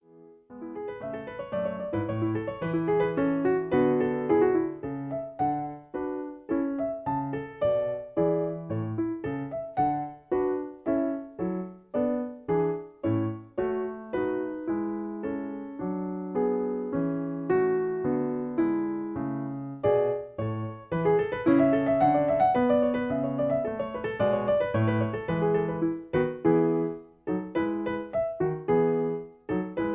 In A. Piano Accompaniment